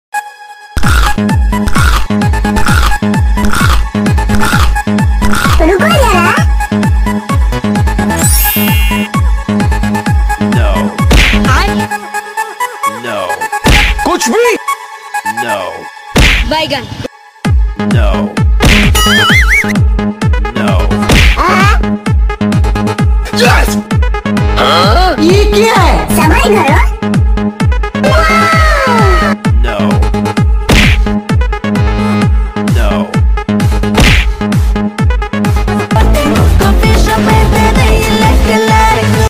Vfx trending, funny vfx, shorts sound effects free download